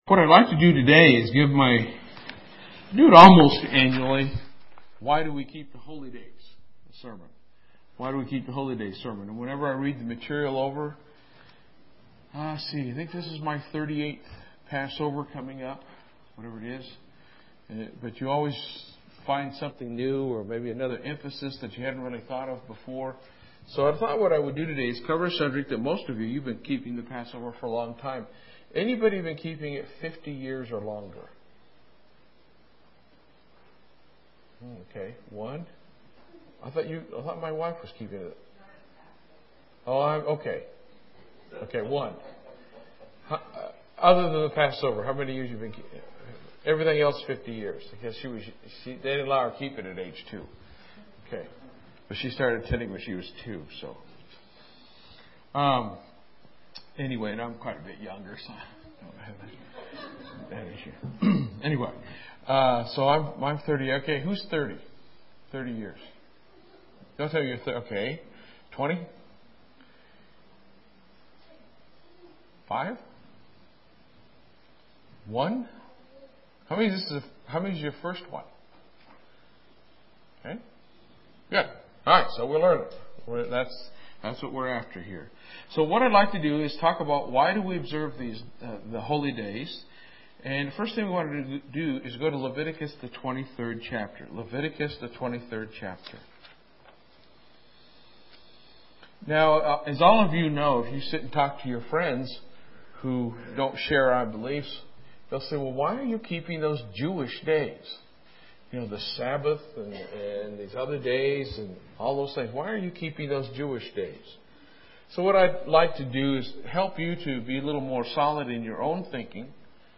Given in Lubbock, TX
UCG Sermon Studying the bible?